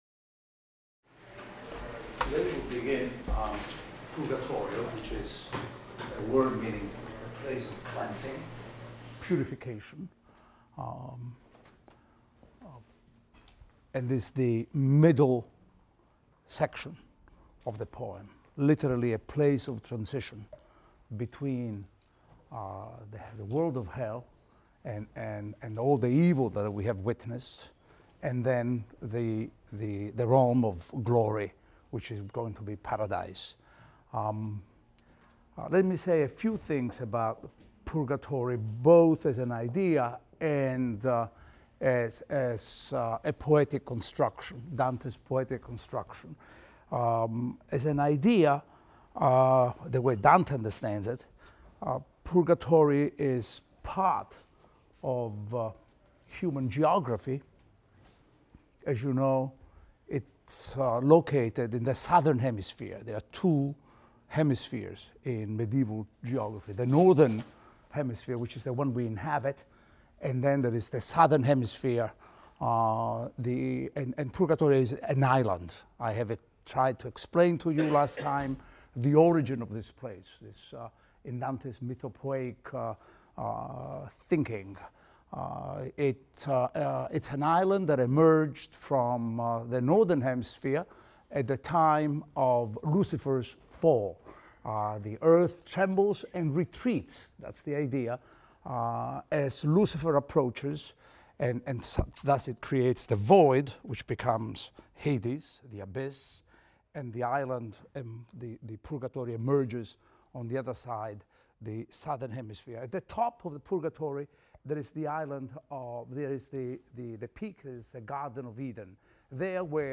ITAL 310 - Lecture 10 - Purgatory I, II | Open Yale Courses